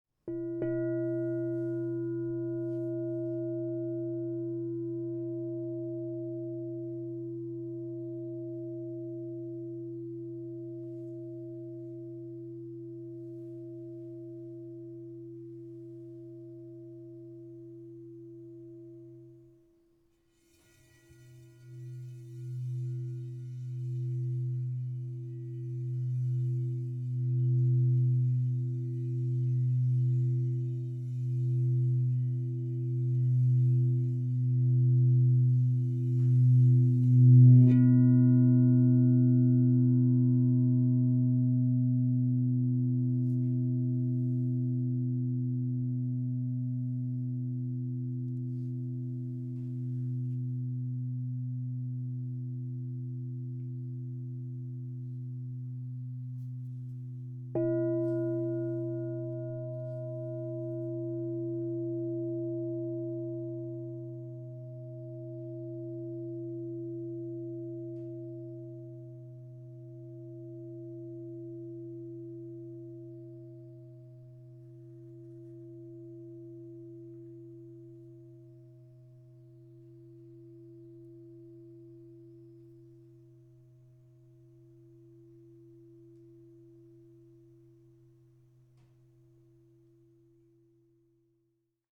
Platinum W/ Intricate Midnight Fairy Dream 12″ B +25 Crystal Tones Singing Bowl
Genuine Crystal Tones® alchemy singing bowl.
528Hz (+)